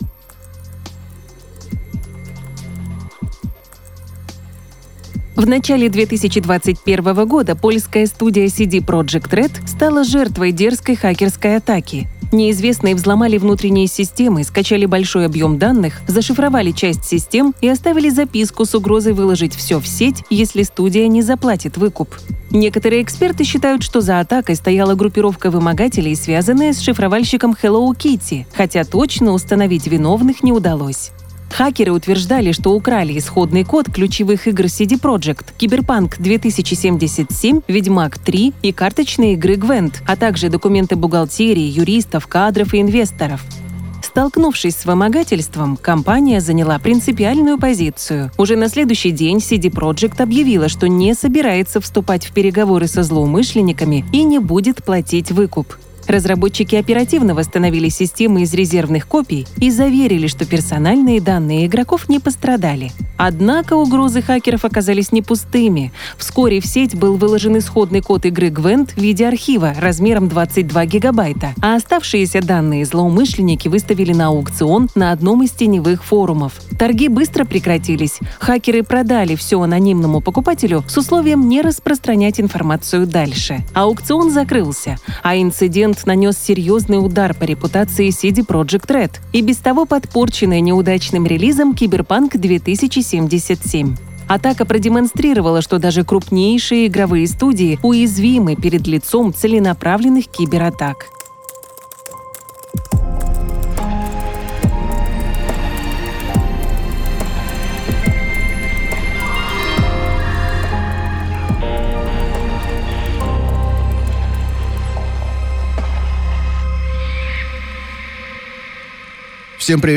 Документально-разговорный подкаст о кибербезопасности.